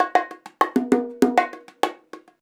100BONG12.wav